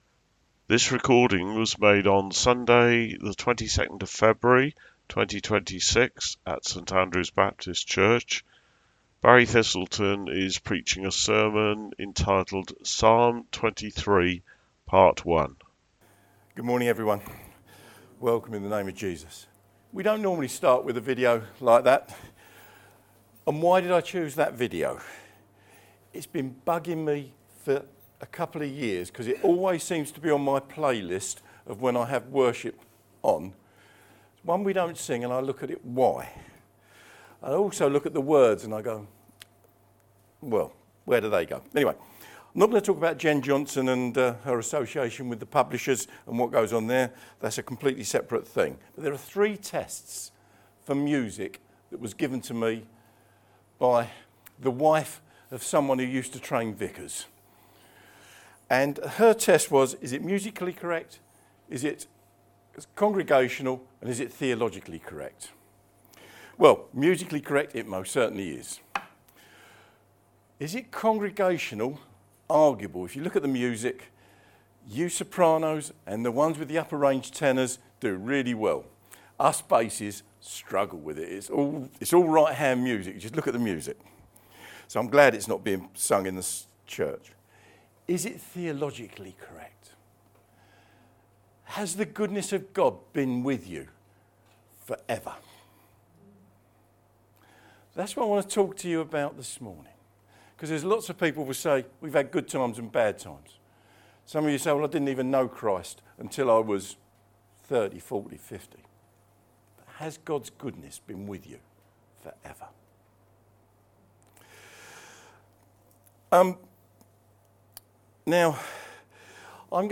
Required fields are marked * Comment * Name * Email * Website Post navigation Published in Sermons February 2026